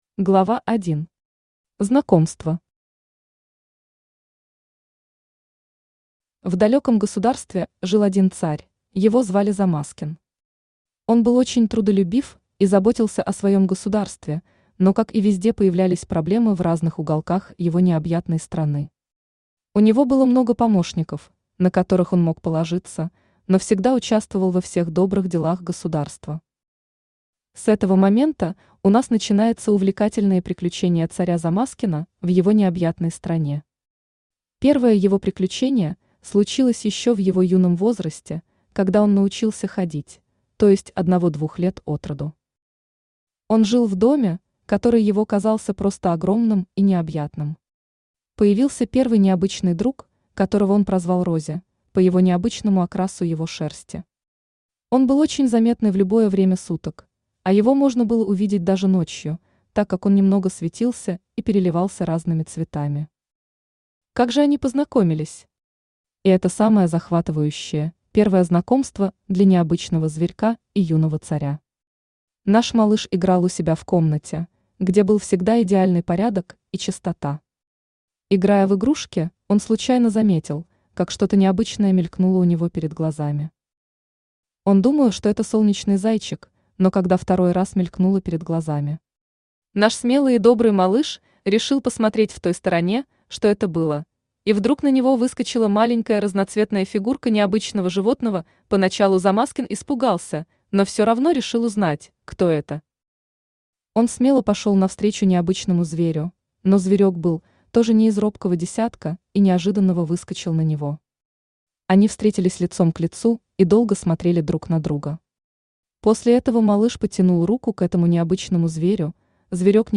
Аудиокнига Приключение Замазкина.
Aудиокнига Приключение Замазкина. Битва за замок Автор Сергей Валентинович Баранкин Читает аудиокнигу Авточтец ЛитРес.